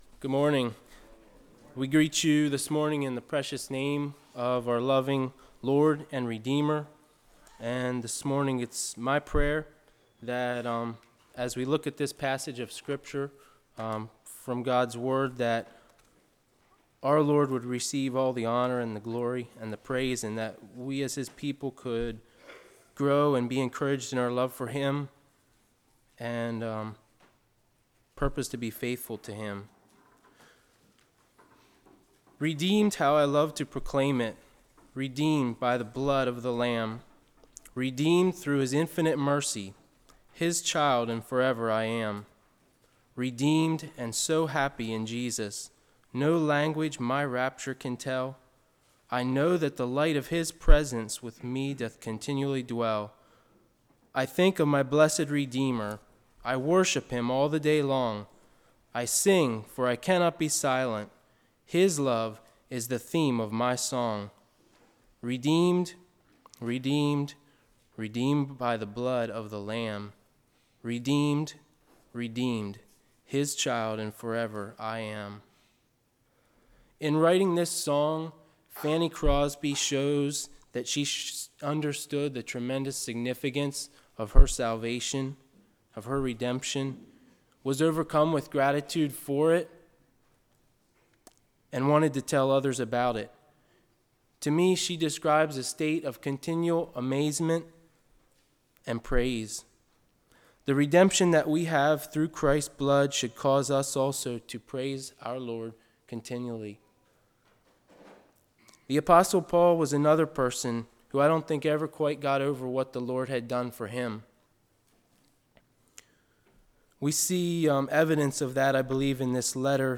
Fall Revival 2013
Service Type: Sunday School